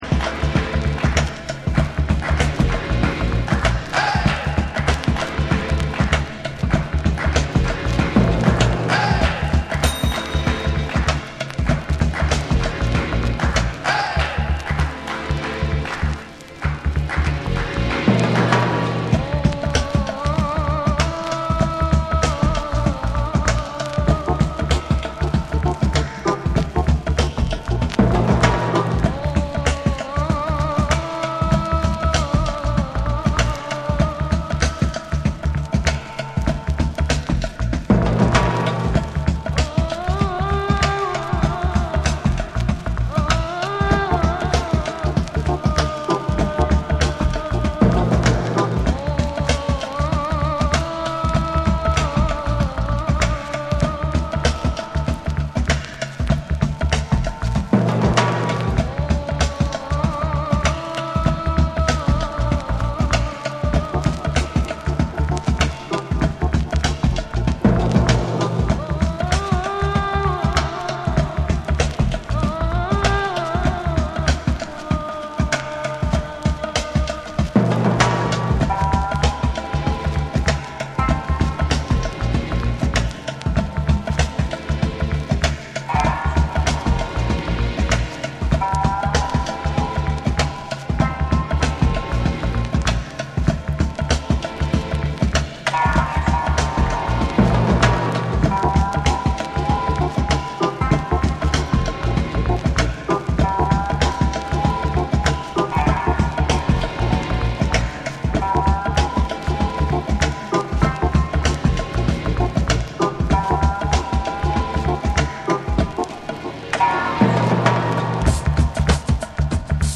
JAPANESE / BREAKBEATS